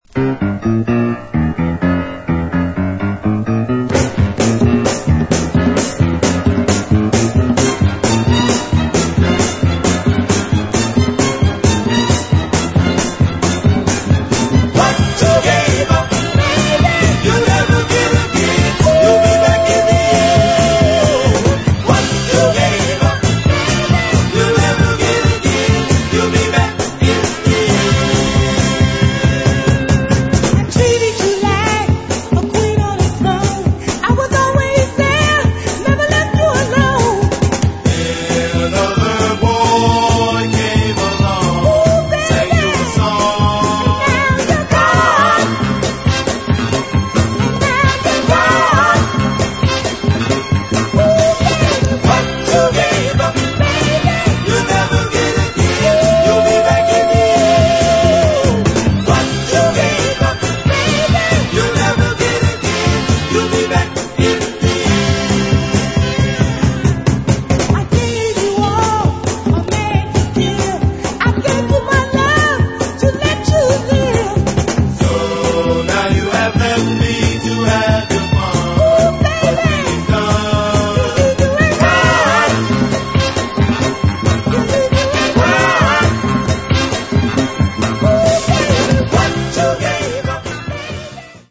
Try this stomping early 70's Philly Northern Soul dancer?
Ex Northern Soul